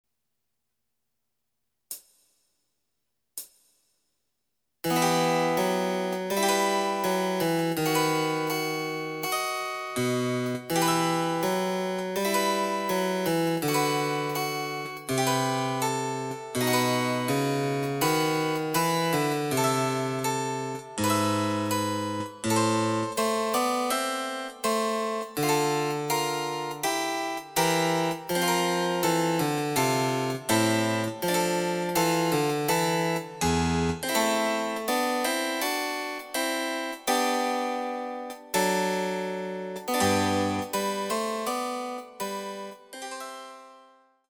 試聴ファイル（伴奏）
ソナタ　第２番　ト長調
デジタルサンプリング音源使用
※フルート奏者による演奏例は収録されていません。